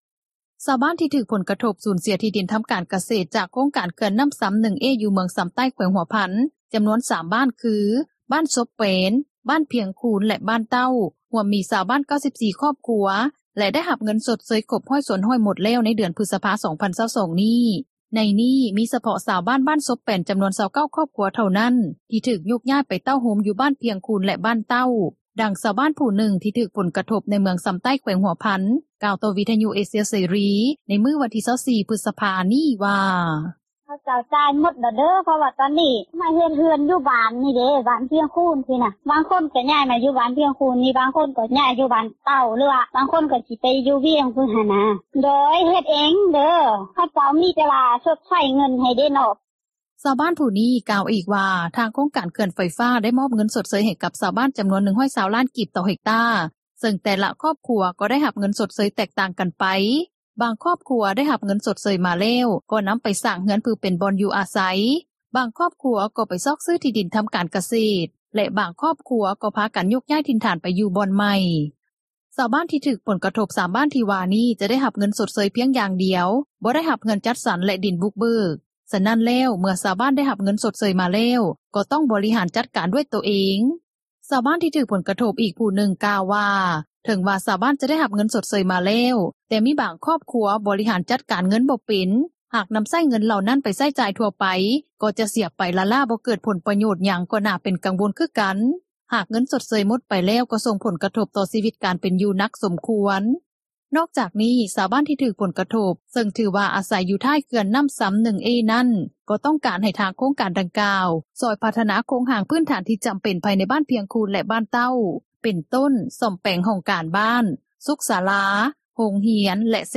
ດັ່ງຊາວບ້ານຜູ້ນຶ່ງ ທີ່ຖືກຜົລກະທົບ ໃນເມືອງຊໍາໃຕ້ ແຂວງຫົວພັນ ກ່າວຕໍ່ວິທຍຸເອເຊັຽເສຣີ ໃນວັນທີ່ 24 ພຶສພາ ນີ້ວ່າ: